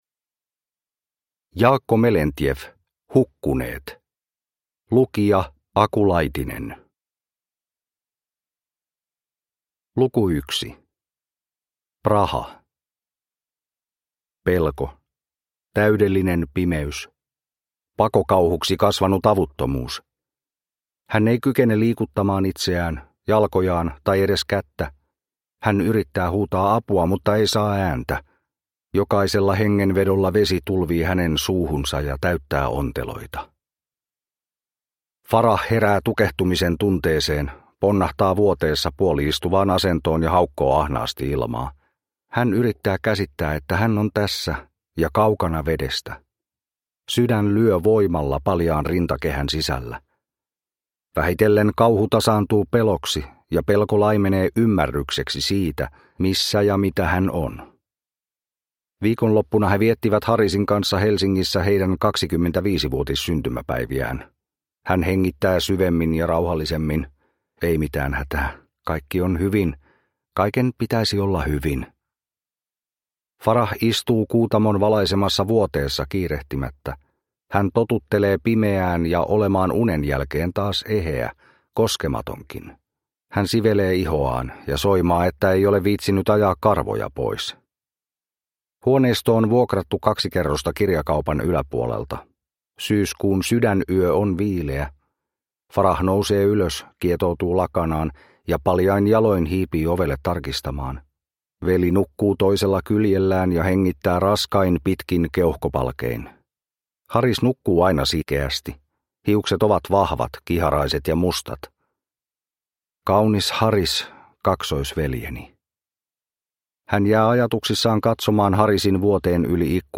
Hukkuneet (ljudbok) av Jaakko Melentjeff